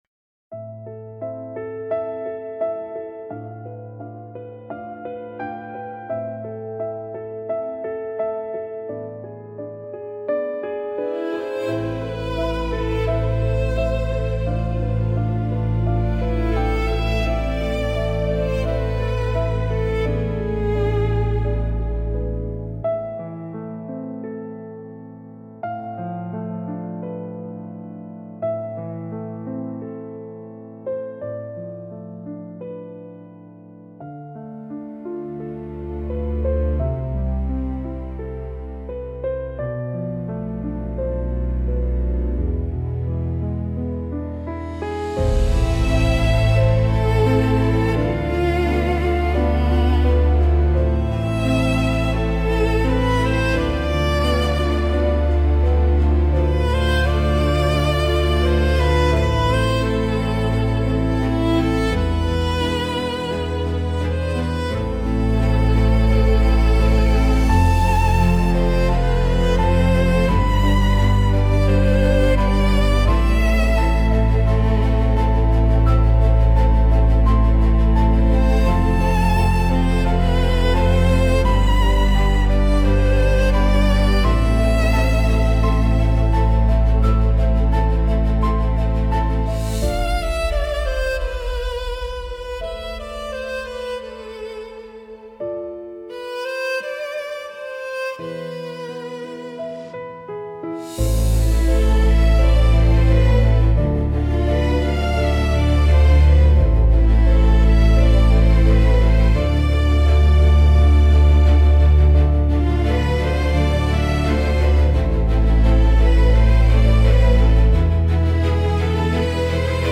ジャンル バラード